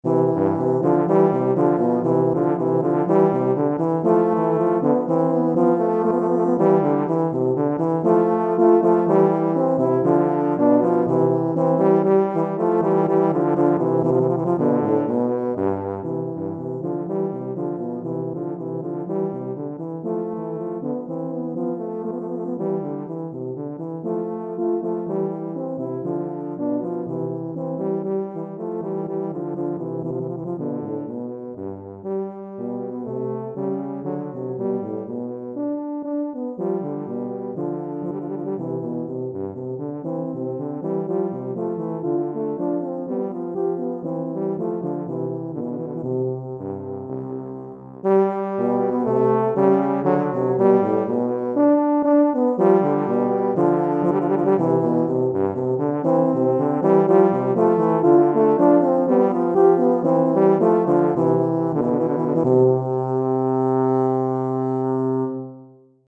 Recueil pour Tuba, euphonium ou saxhorn - 2 Tubas